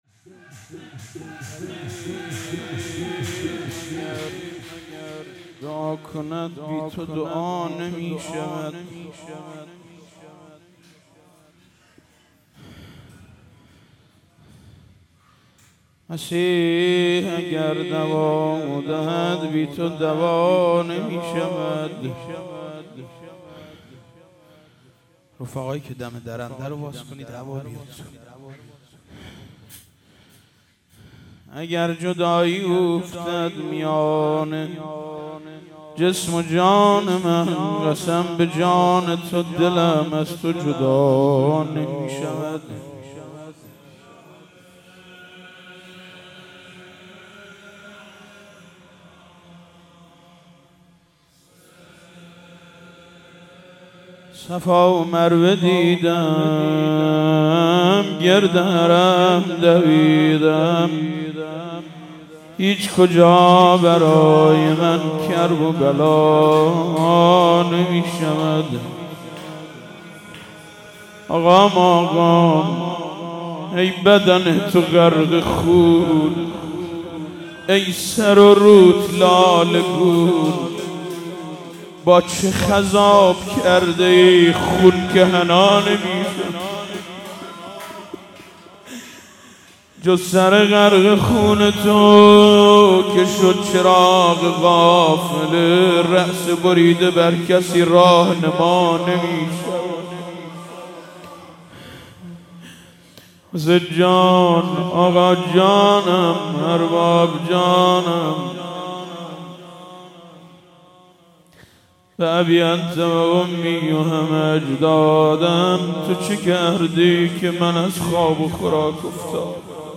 مراسم هفتگی/6دی97